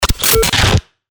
FX-1566-BREAKER
FX-1566-BREAKER.mp3